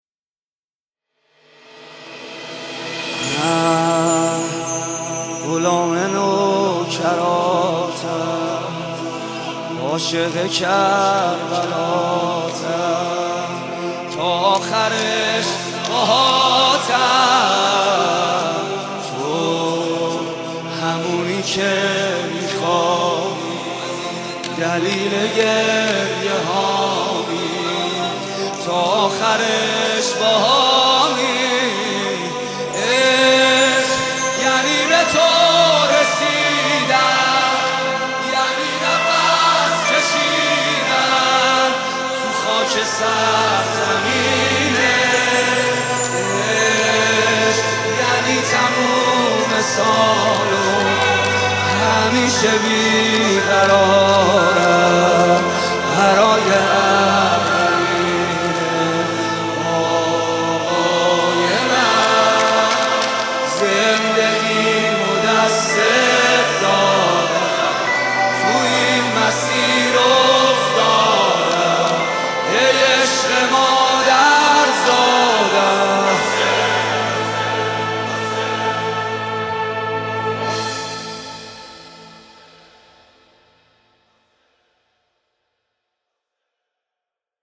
برچسب هاتک آهنگ ، نوحه